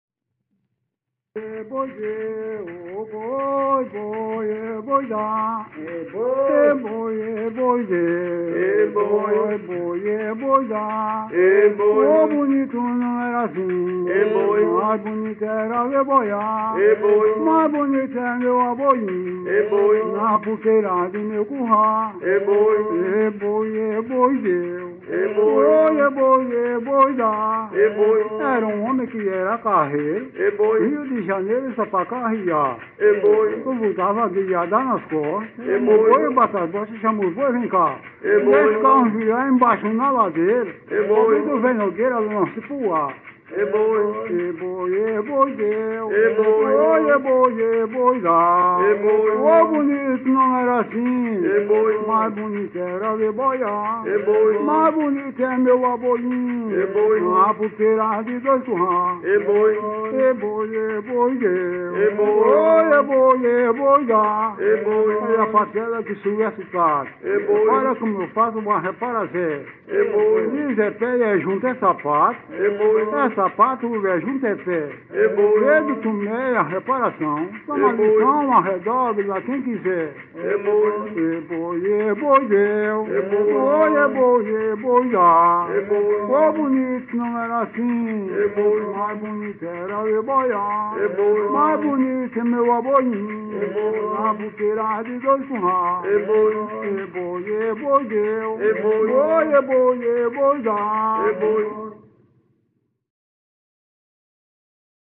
Coco sotaque -..É boi""